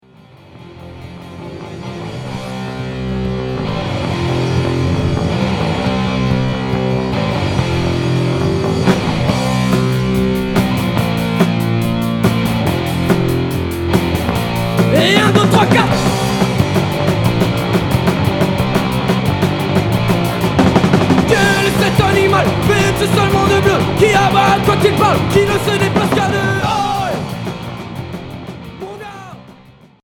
Punk oi Unique 45t retour à l'accueil